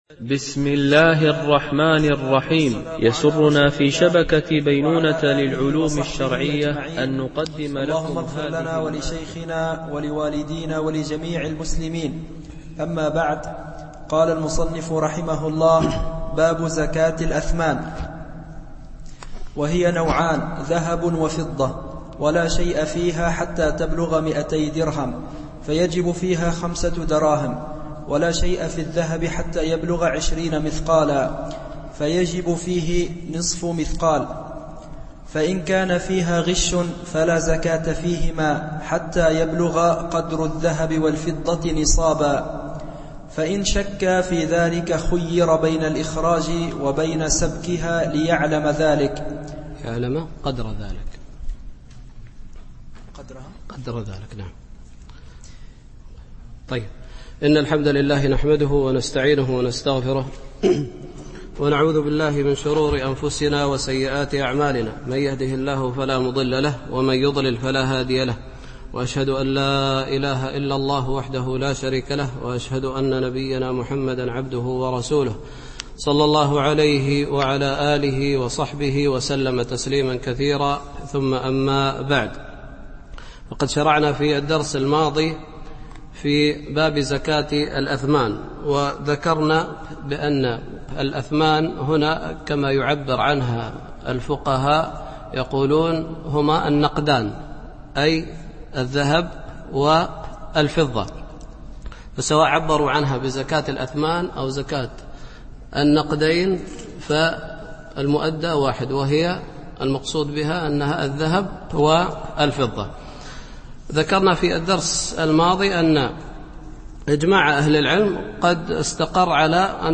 شرح عمدة الفقه ـ الدرس 49